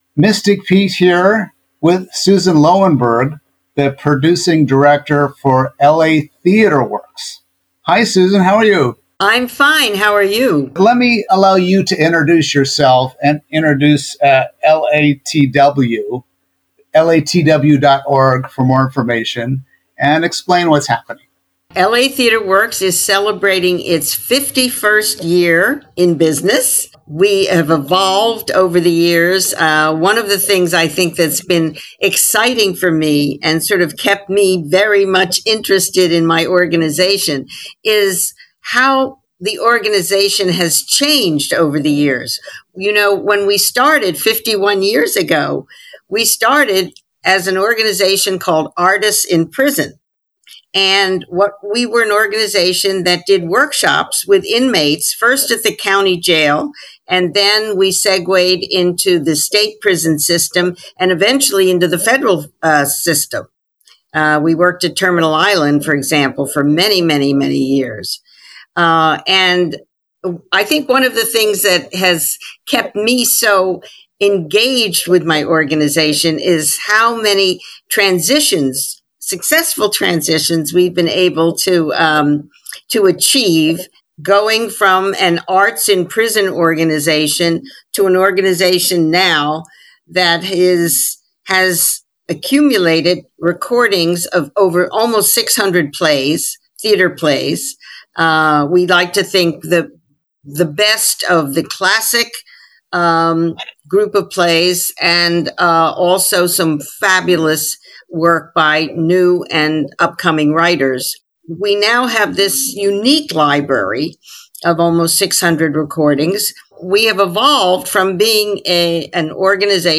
L.A. Theatre Works {interview only}